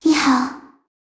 语音合成-接口文档